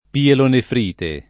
pielonefrite [ pielonefr & te ] s. f. (med.)